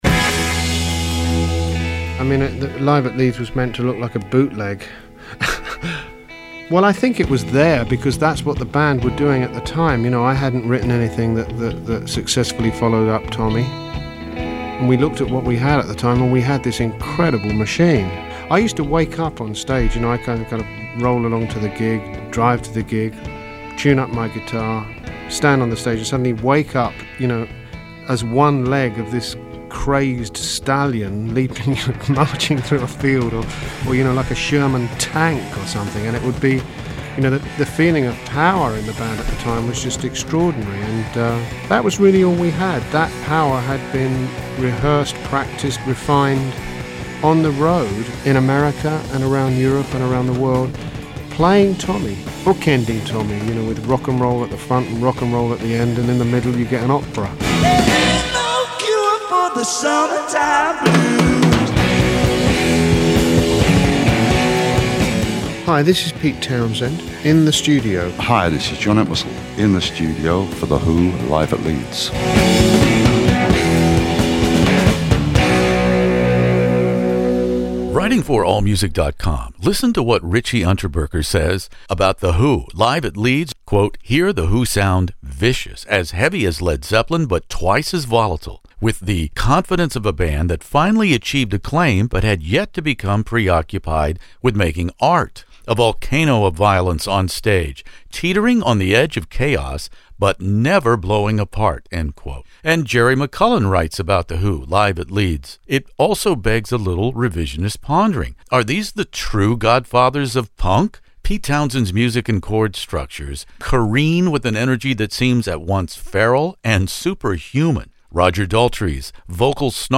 The Who Live at Leeds interview with Pete Townshend, Roger Daltrey, and the late John Entwistle.